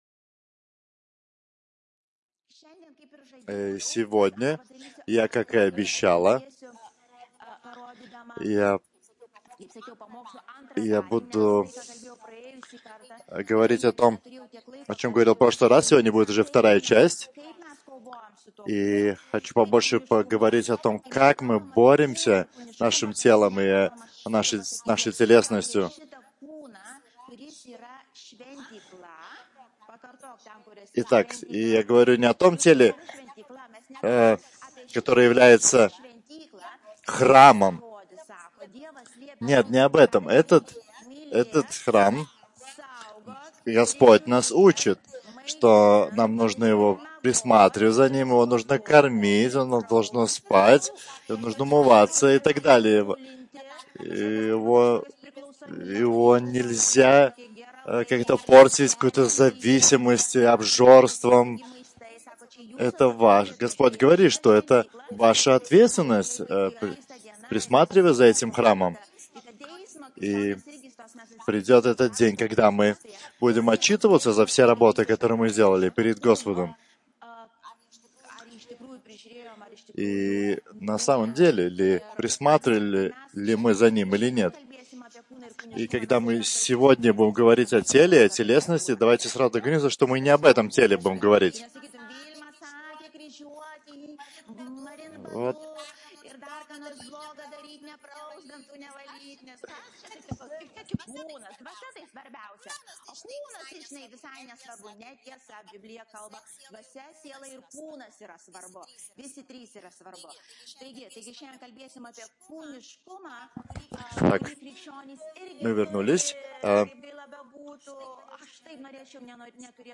PAMOKSLO ĮRAŠAS MP3